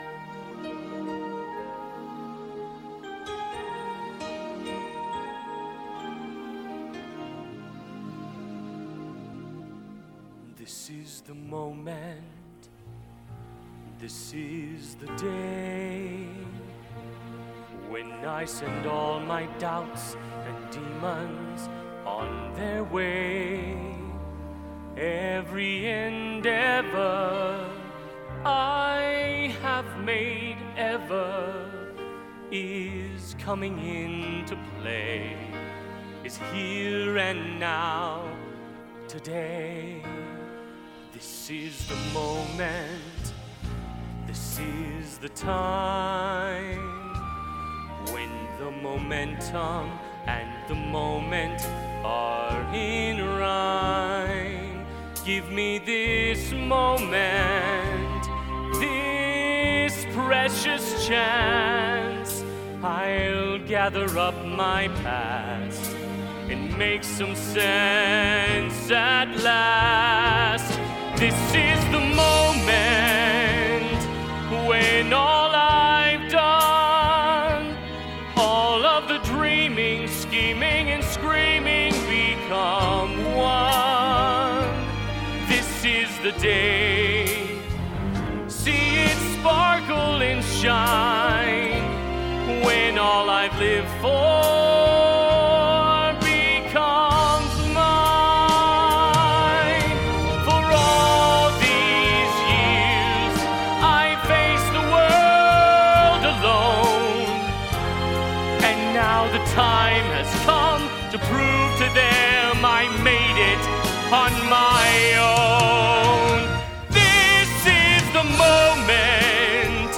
Recordings from Show